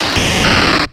Audio / SE / Cries / DRAPION.ogg